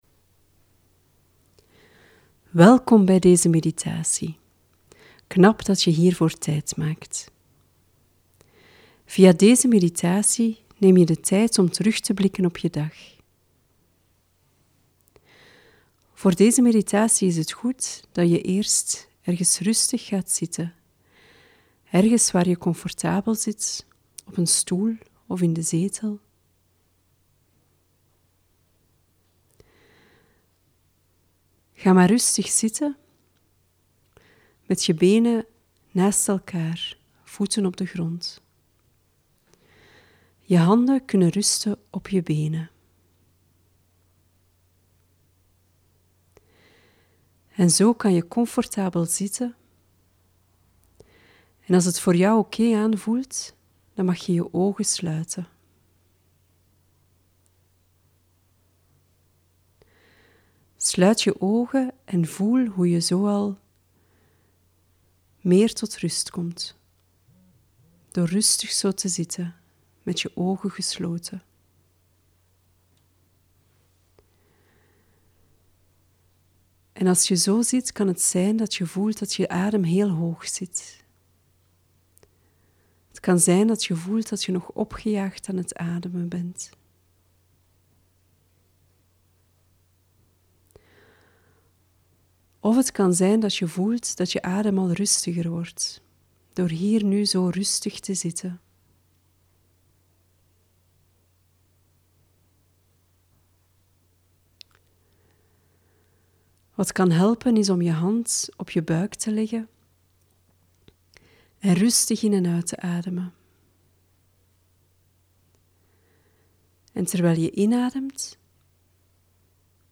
Een begeleide meditatie- of visualisatie-oefening kan je hierbij helpen.